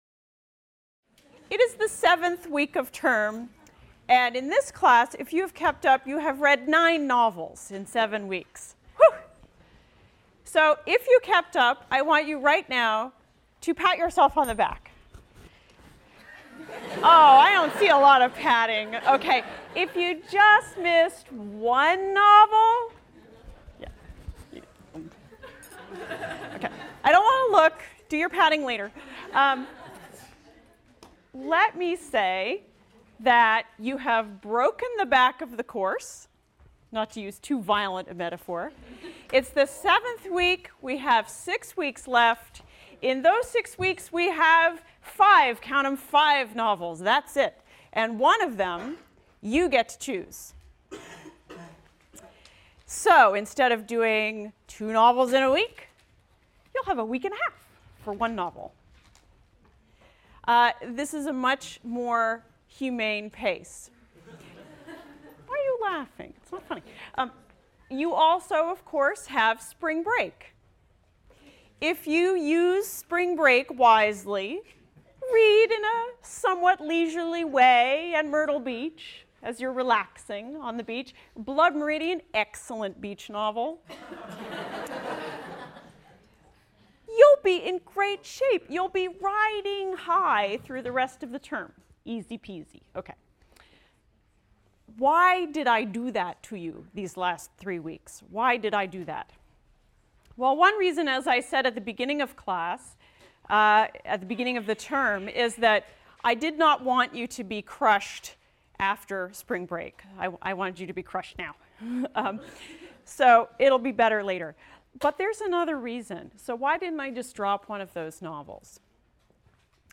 ENGL 291 - Lecture 14 - Maxine Hong Kingston, The Woman Warrior | Open Yale Courses